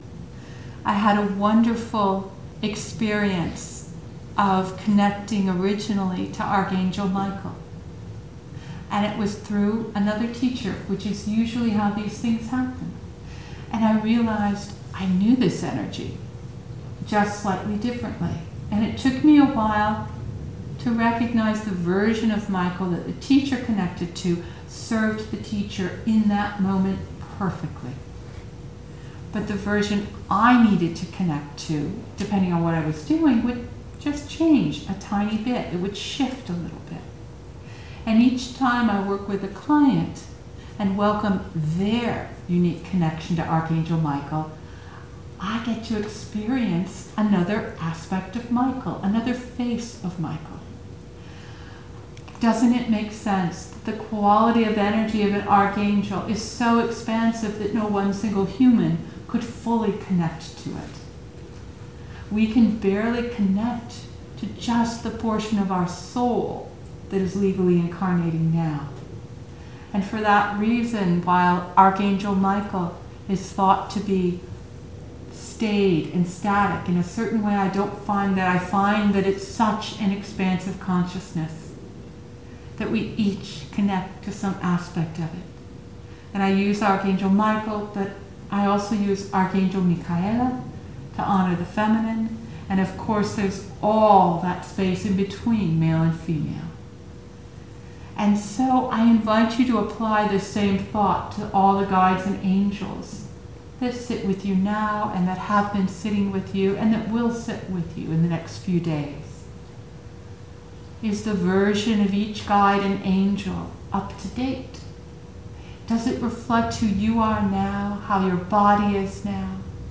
playing tambura and harmonium